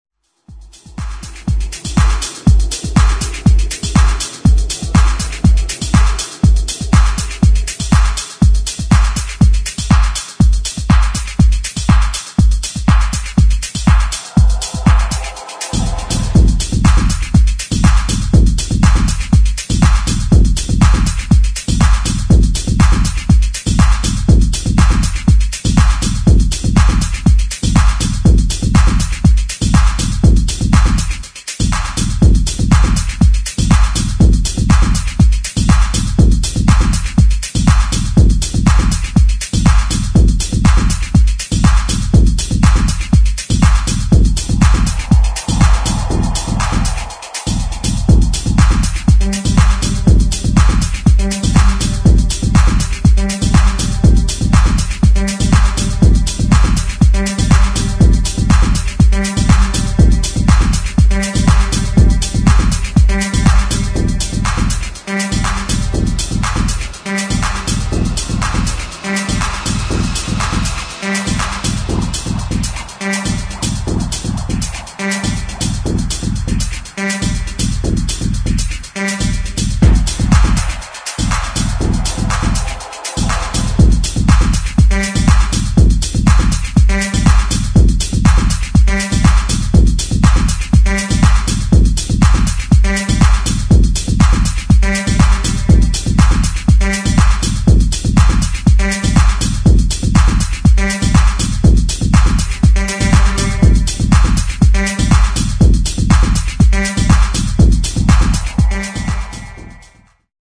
[ TECH HOUSE ]